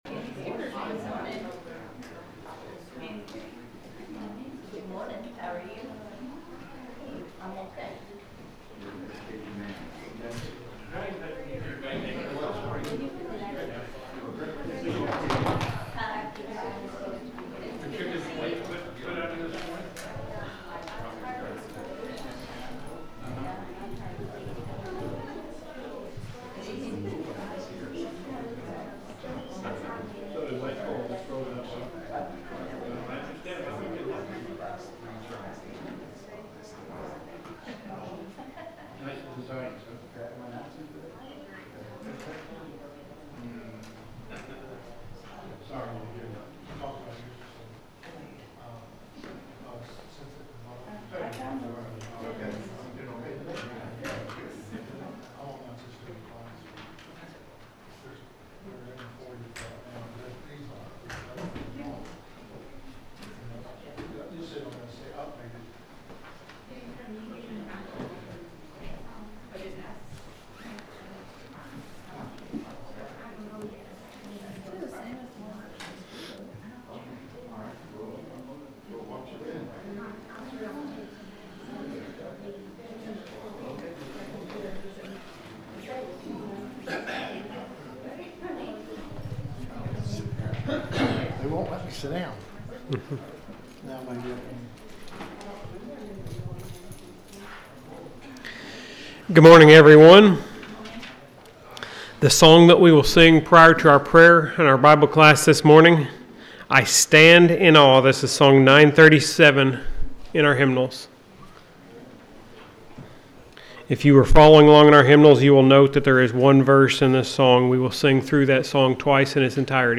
The sermon is from our live stream on 2/8/2026